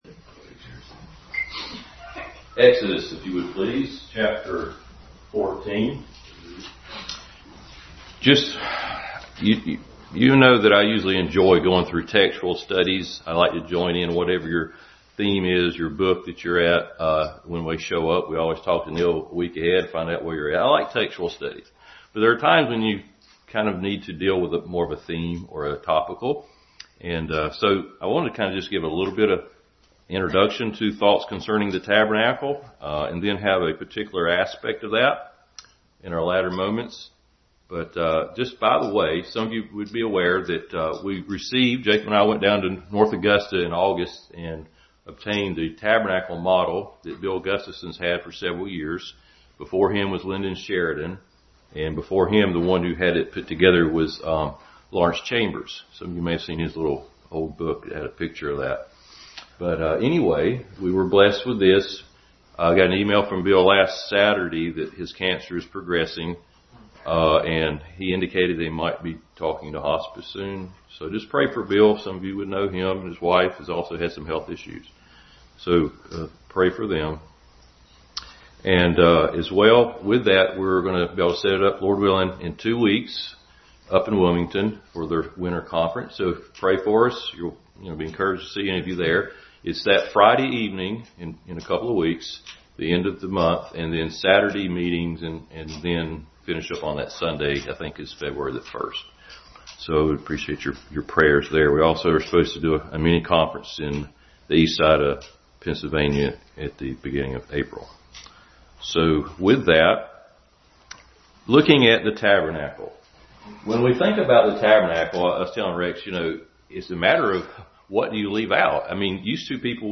Passage: Exodus 14:19, 13:20-22, 16:10, 24:15-18, 25:8, 40:2, 34-38, Numbers 10:11-13, 14:14, 16:9, 41-43, Leviticus 16:2, 1 Corinthians 10:1 Service Type: Family Bible Hour